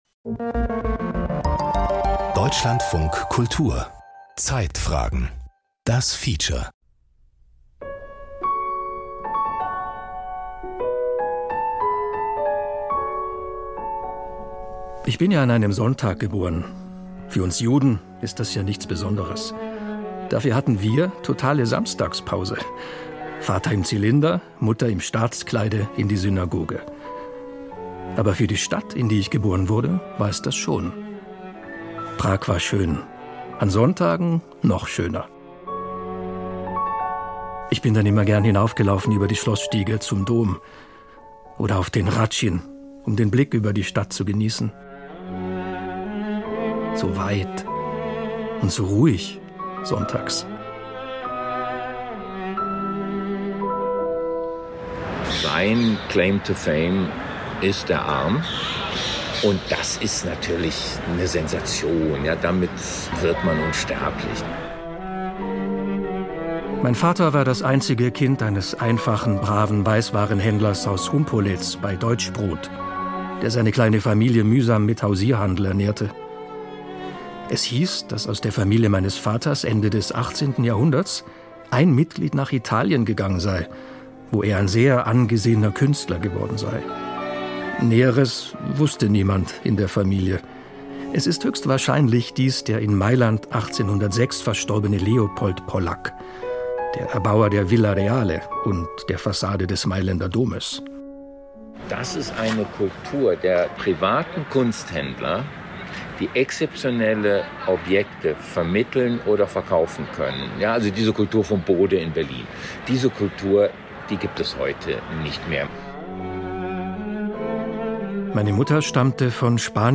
Feature Podcast abonnieren Podcast hören Podcast Zeitfragen-Feature Unsere Welt ist komplex, die Informationsflut überwältigend.